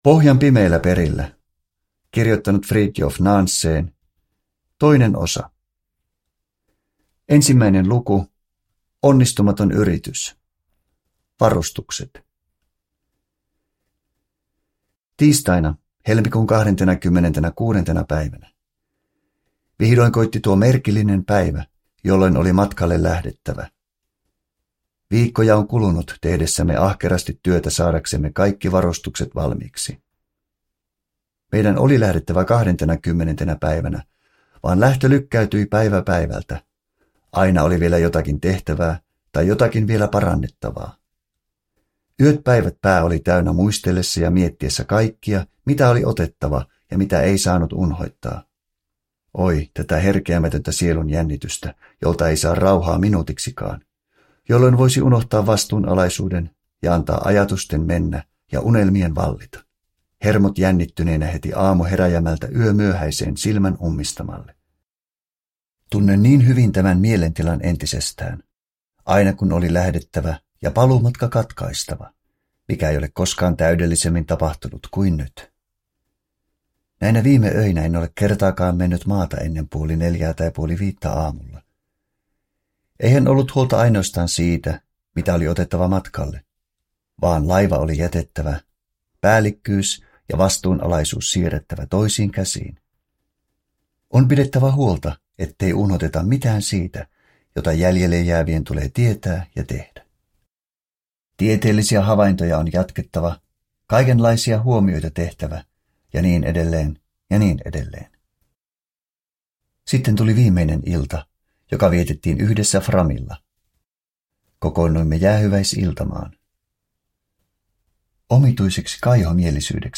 Pohjan pimeillä perillä. Norjalainen Napaseuturetki 1893—1896. Osa 2. – Ljudbok